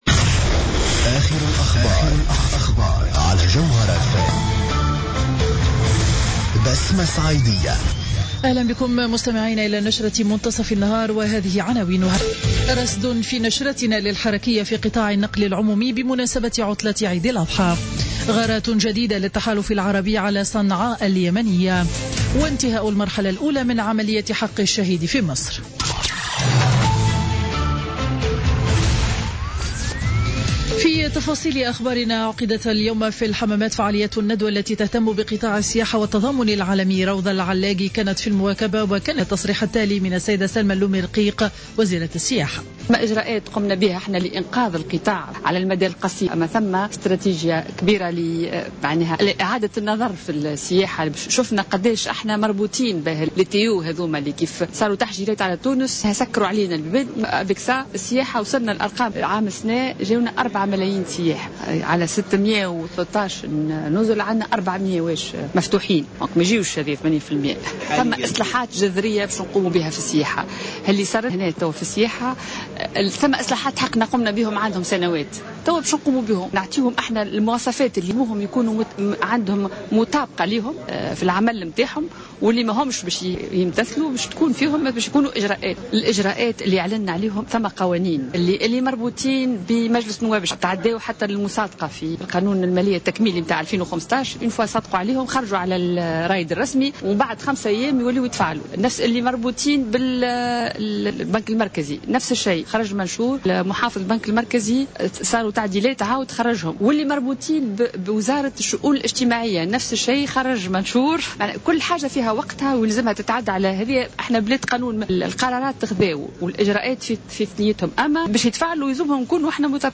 نشرة أخبار منتصف النهار ليوم الأربعاء 23 سبتمبر 2015